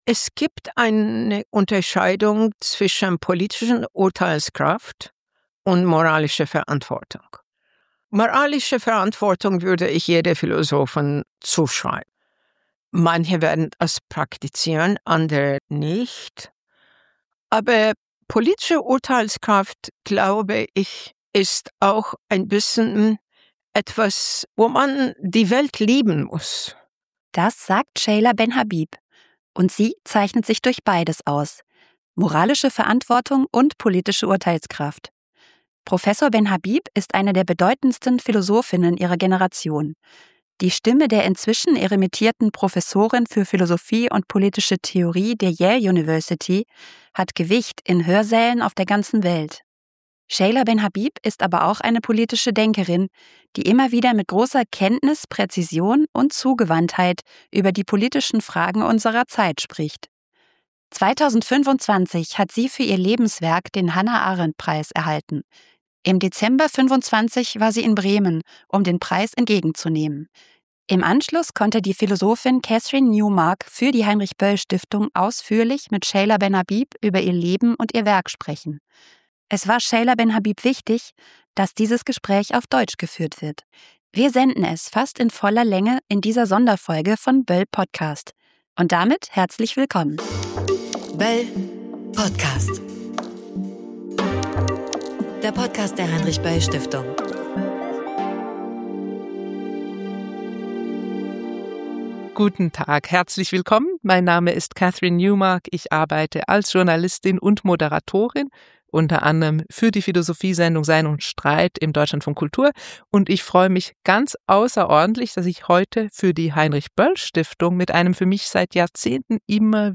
Gespräch mit der Hannah-Arendt-Preisträgerin Seyla Benhabib ~ böll.podcast Podcast
Wir senden dieses Gespräch fast in voller Länge als Interview-Spezial im böll.podcast. Mehr